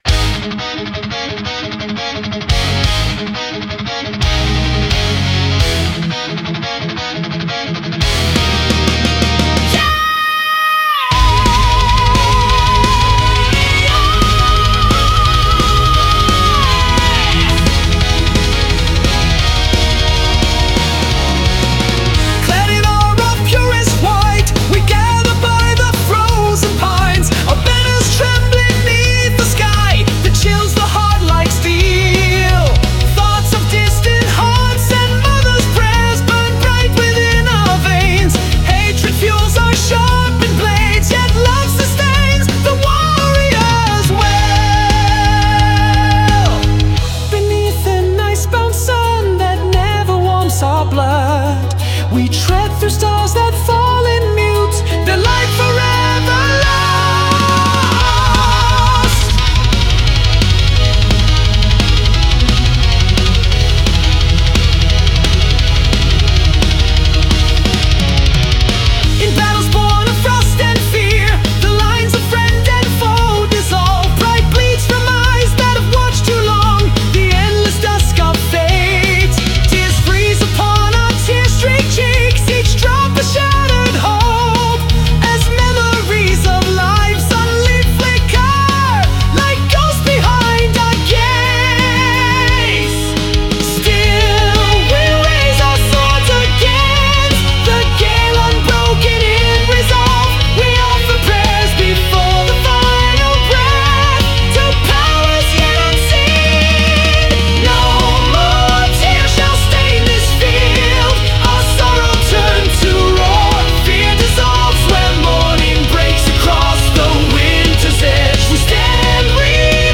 Melodic Power Metal
• 2026-04-26 Remastered